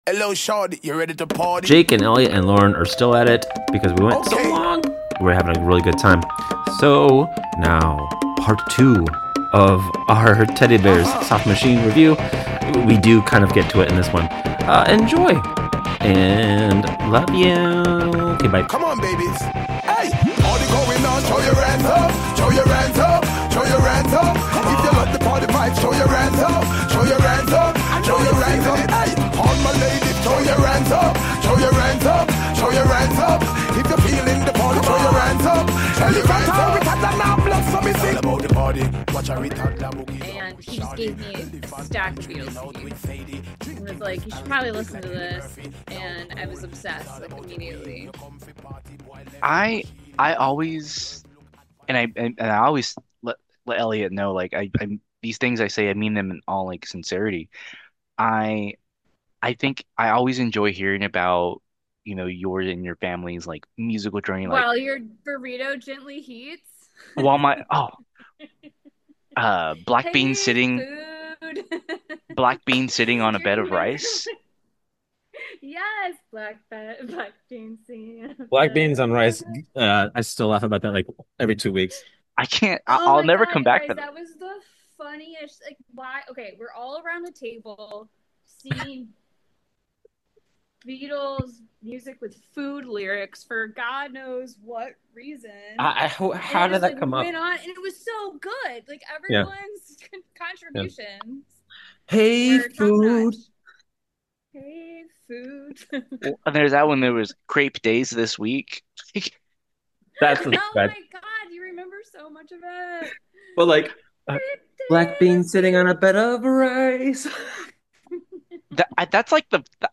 A truly lovely conversation about everything.